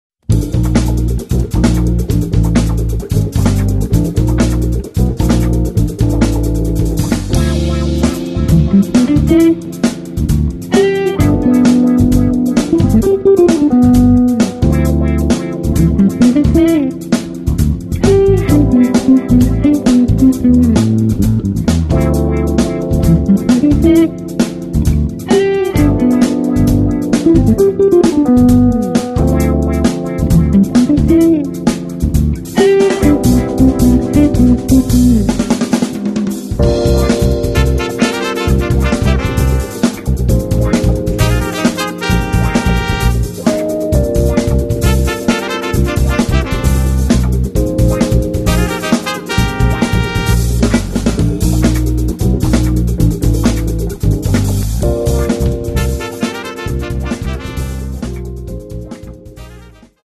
Registrato presso lo “Orange Bug” studio di Napoli